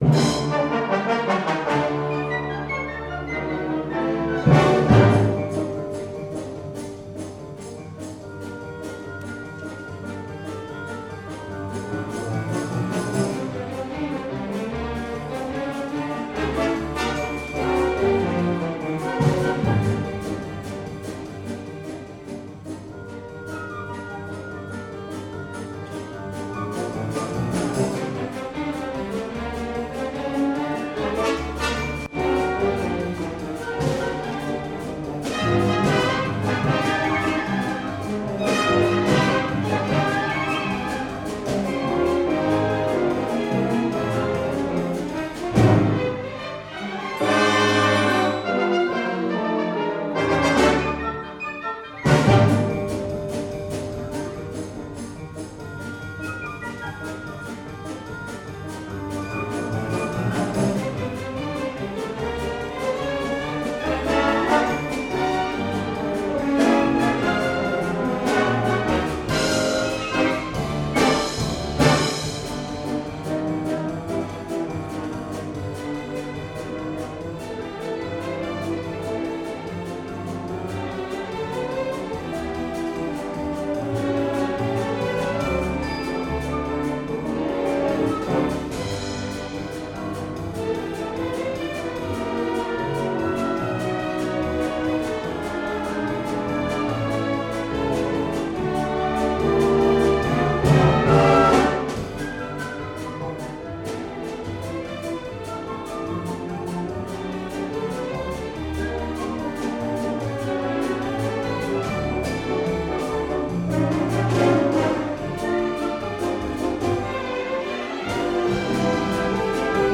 07/01/2007 Maison des Arts et Congrès à Niederbronn-les-bains